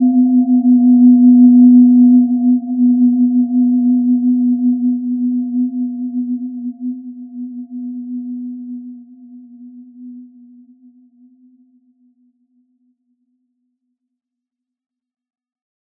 Gentle-Metallic-3-B3-p.wav